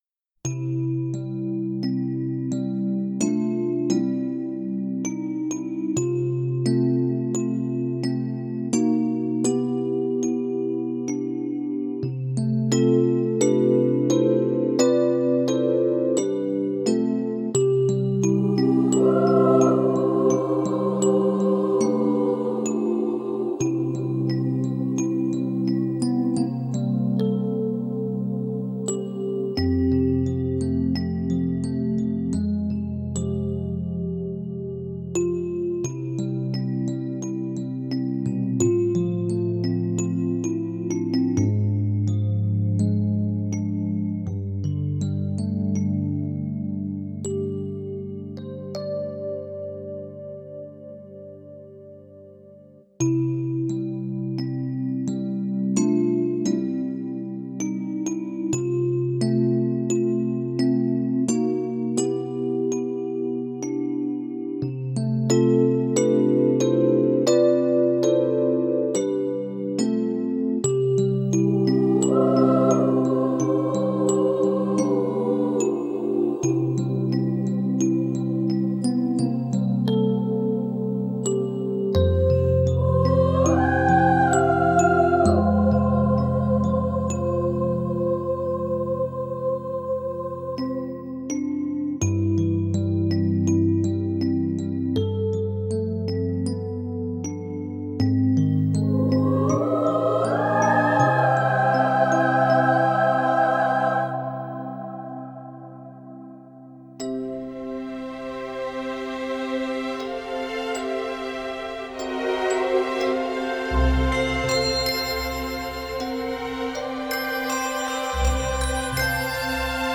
Стиль: NewAge, Christmas